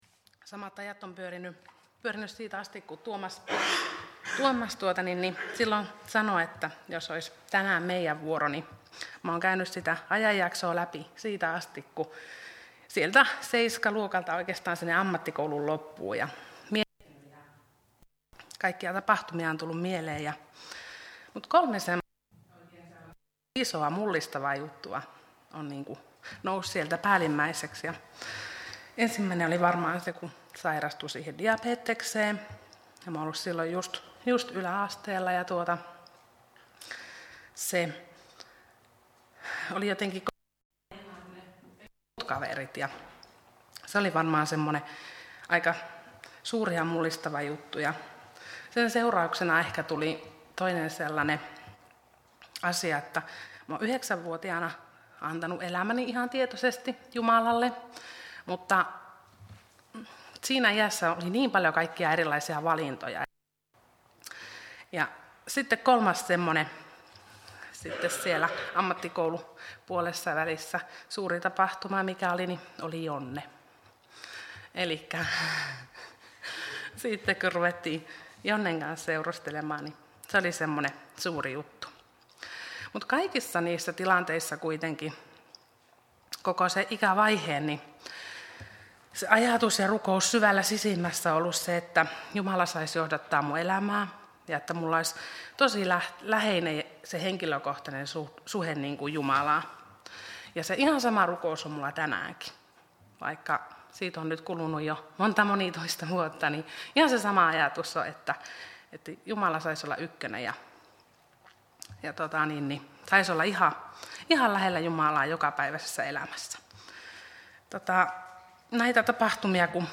Tästä voit kuunnella Soinin Helluntaiseurakunnassa pidettyjä todistuspuheenvuoroja: